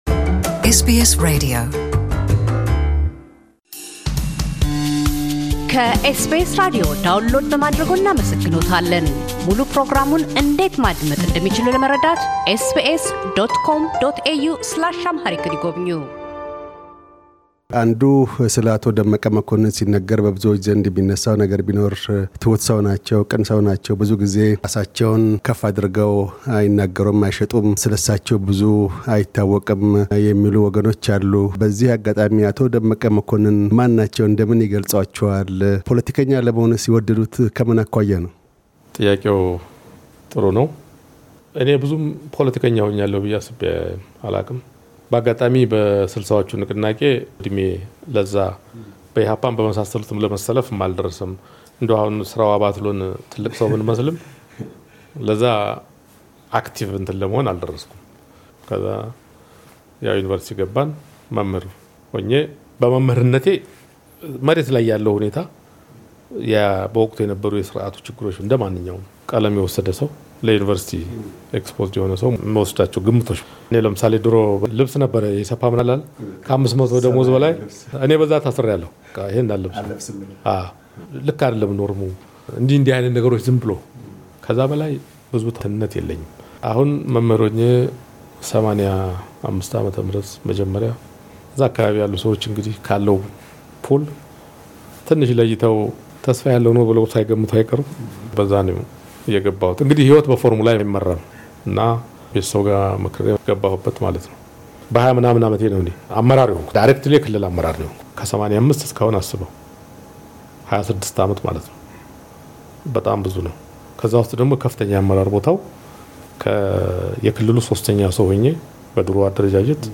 ምልሰታዊ ምልከታ፤ በክፍል ሁለት ቀጣይና መደምደሚያ ቃለ ምልልሳችን፤ ምክትል ጠቅላይ ሚኒስትር ደመቀ መኮንን ከመምህርነት ወደ ፖለቲካው ዓለም እንደምን ተስበው እንደዘለቁ፤ ወደ ቁጥር ሁለት የሥልጣን እርከን ላይ እንዴት እንደደረሱ፤ በምን ሳቢያ “አባ መላ” እንደተሰኙ፤ ባለፉት ሶስት የለውጥ ንቅናቄ ዓመታት የነበሩትን ፈታኝ ሁኔታዎች፣ የእሳቸውን መንታ መንገድ ላይ መድረስና ሚና አንስተው ይናገራሉ።